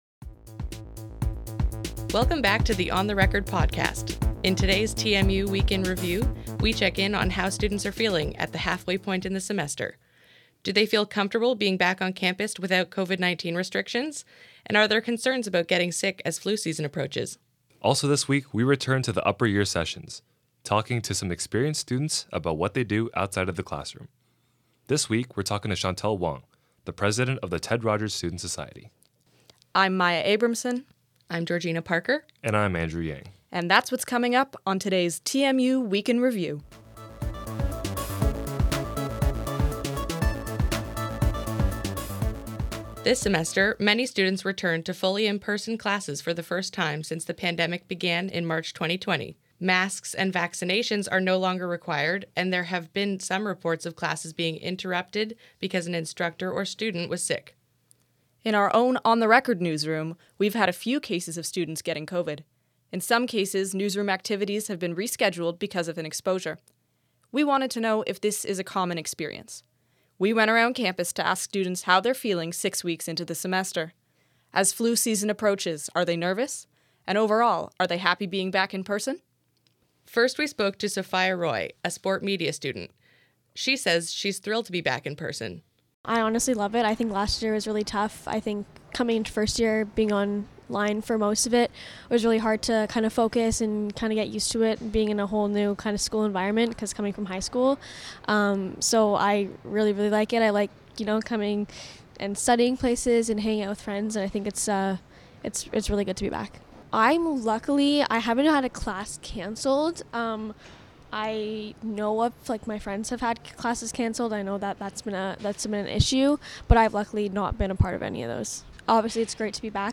It’s the halfway mark for the fall semester at TMU. We ask students how they are feeling being back on campus without restrictions and whether they’ve had any interruptions to classes due to people getting sick.